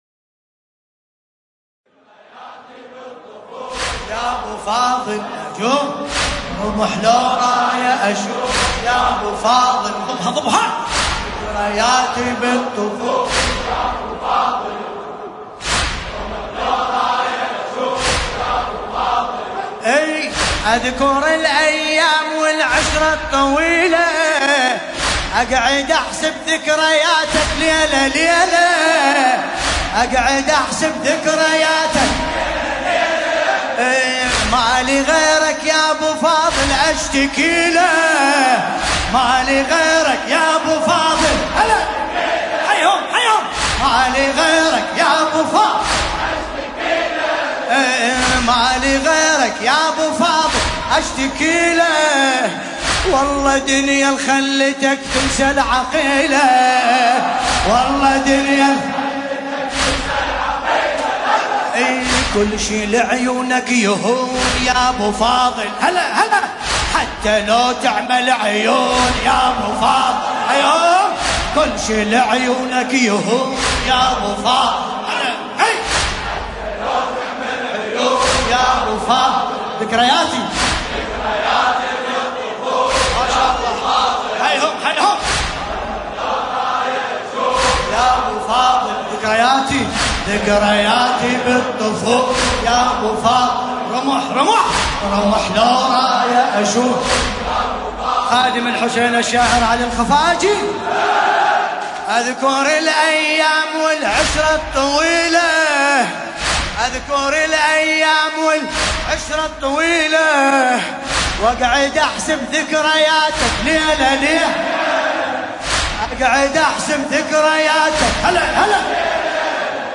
المناسبة : ليلة 18 محرم 1440 هـ